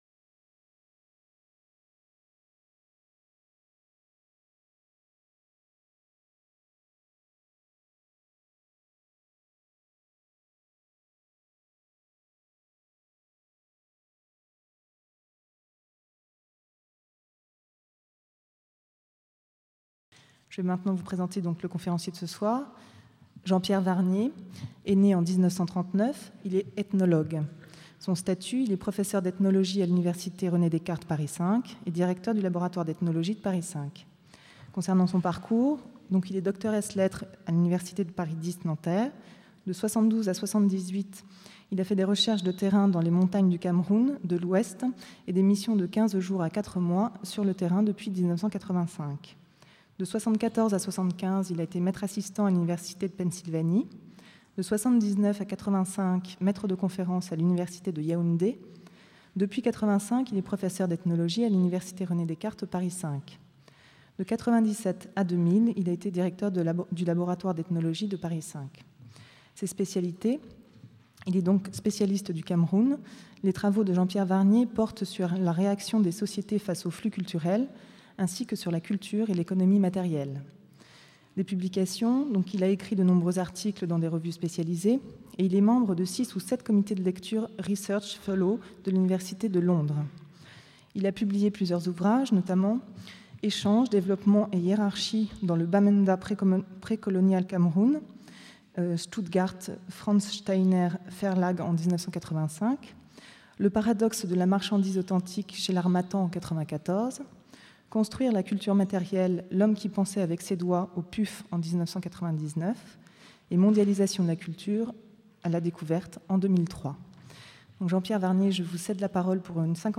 Ils sont en général mal compris des publics auxquels je m'adresse, et c'est sur ces deux points que portera la conférence.